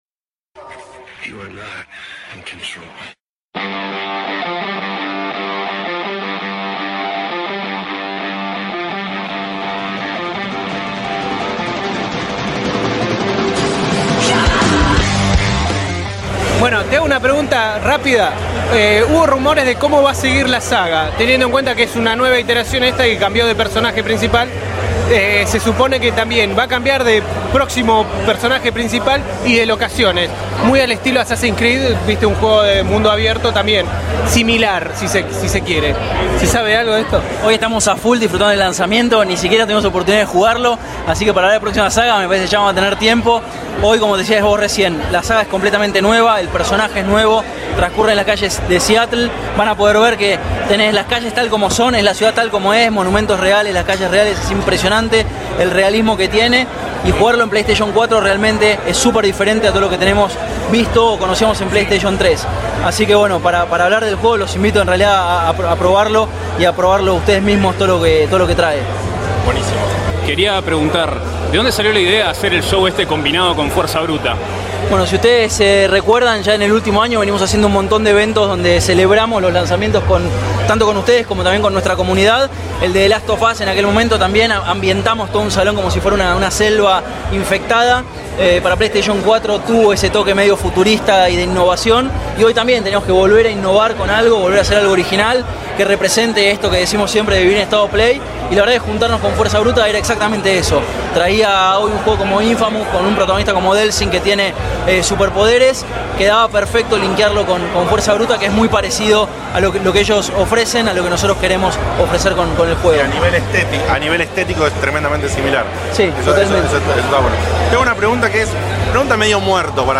Checkpoint dió presente en el evento organizado por la gente de Sony por el lanzamiento en nuestro país del primer juego que te puede motivar verdaderamente a comprarte una PS4, Infamous: Second Son.